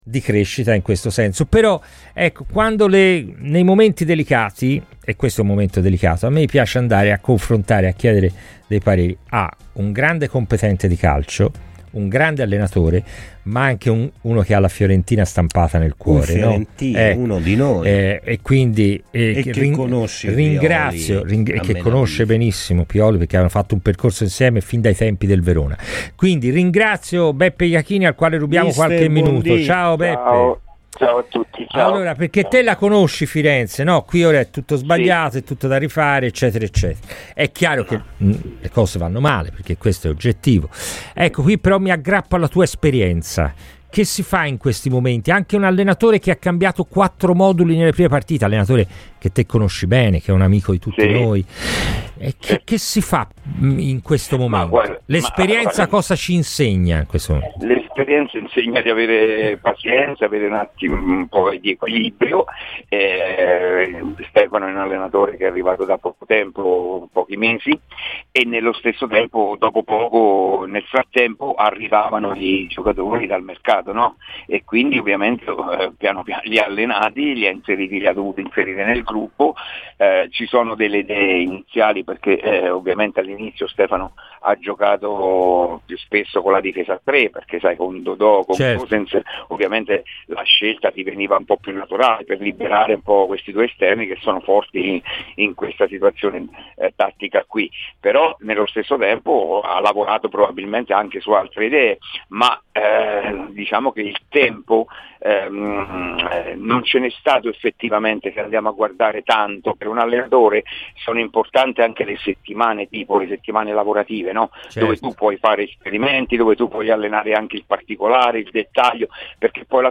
Su Radio FirenzeViola, durante Palla al Centro, parola a Beppe Iachini.